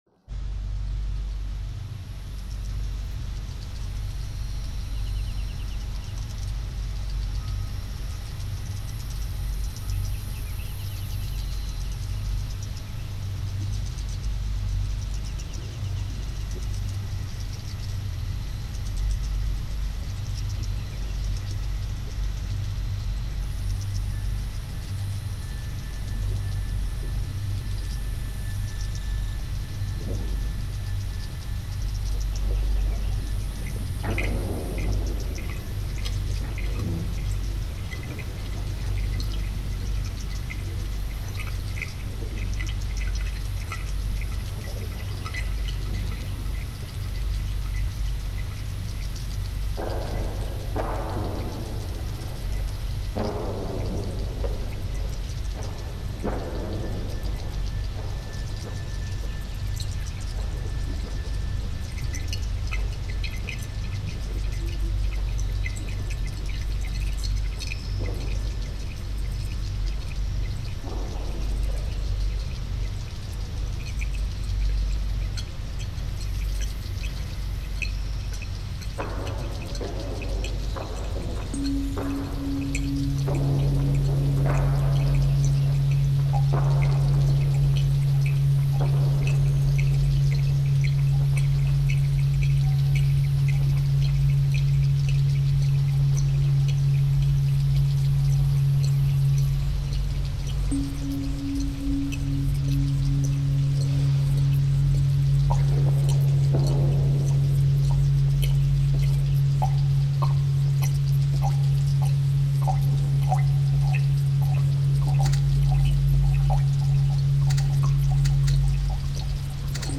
fuses goa-trance, techno, and ambient music